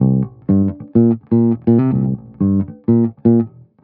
22 Bass Loop C.wav